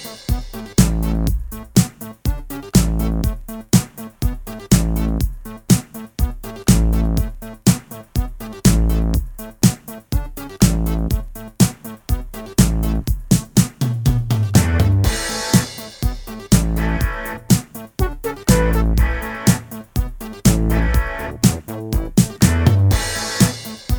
no Backing Vocals Reggae 3:46 Buy £1.50